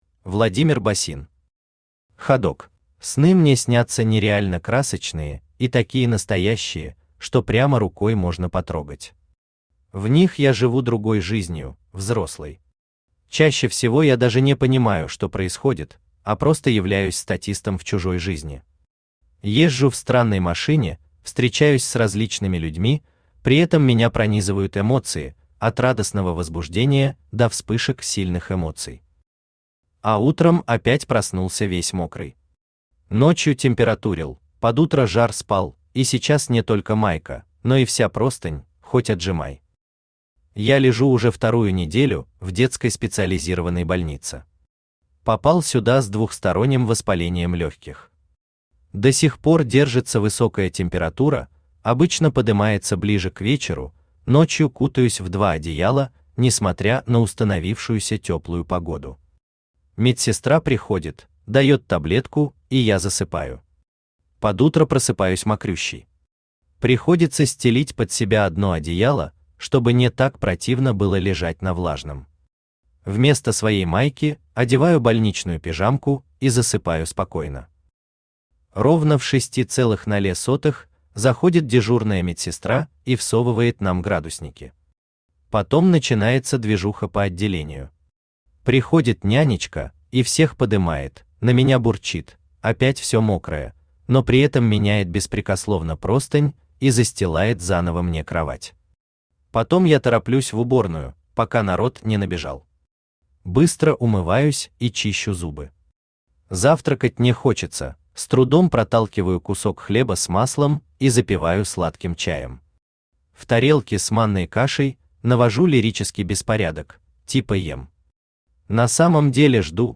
Aудиокнига Ходок Автор Владимир Георгиевич Босин Читает аудиокнигу Авточтец ЛитРес.